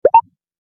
Text Pop-Up Sound Effect
Description: Text pop-up sound effect. Short alert tone. Perfect for pop-up messages, reminders, or on-screen appearance cues.
Text-pop-up-sound-effect.mp3